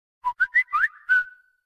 Category: Samsung Ringtones